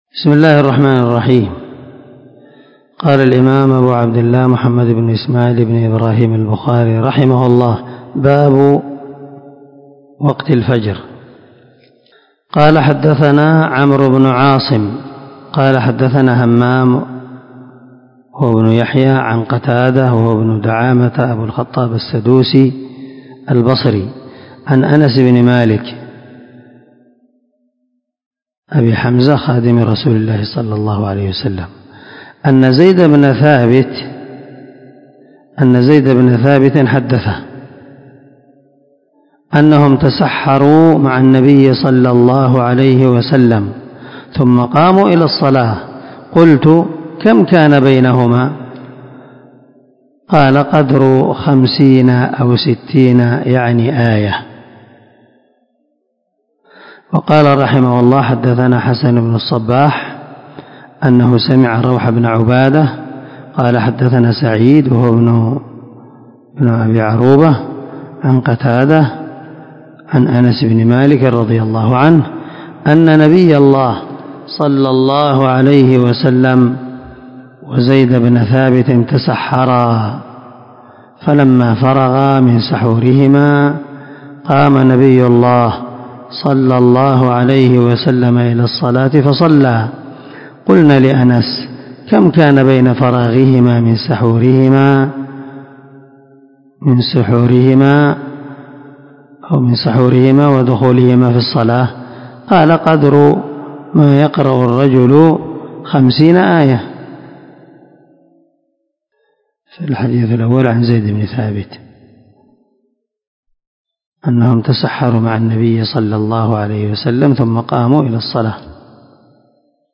405الدرس 35 من شرح كتاب مواقيت الصلاة حديث رقم ( 575 – 577 ) من صحيح البخاري
دار الحديث- المَحاوِلة- الصبيحة.